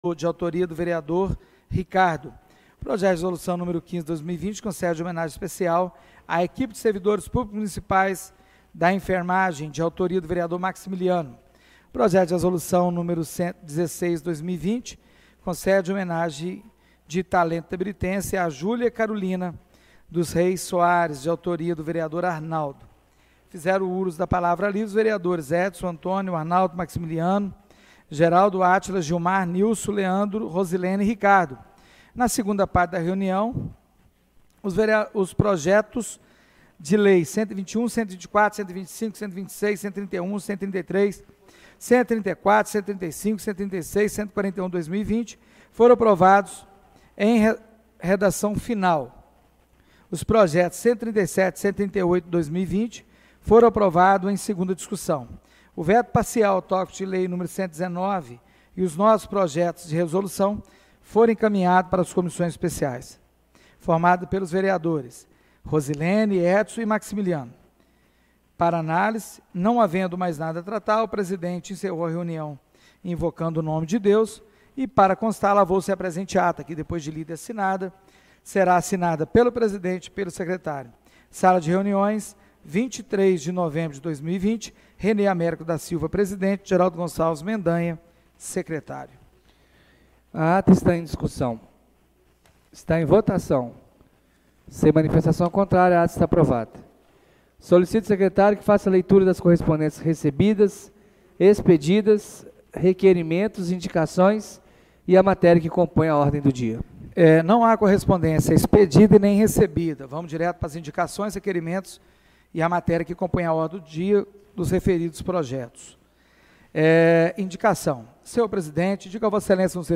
Reunião Ordinária do dia 23/11/2020 — Câmara Municipal de Itabirito